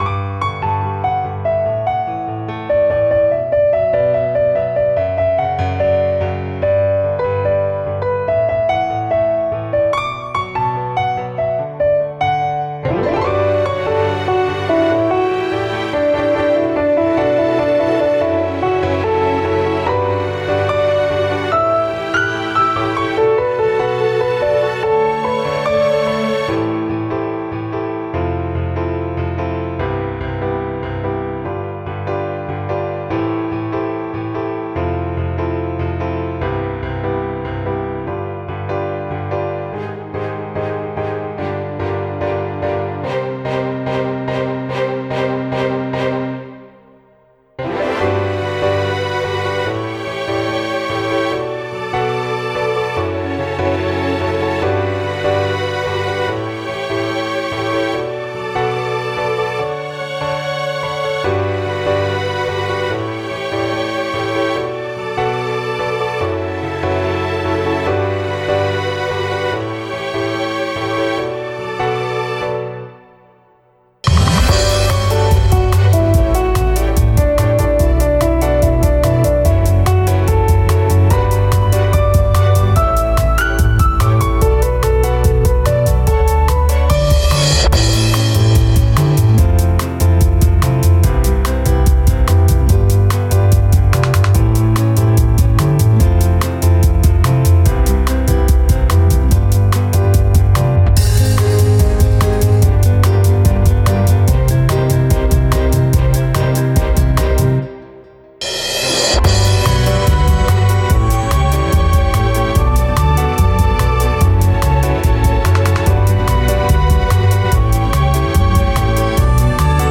BPM：145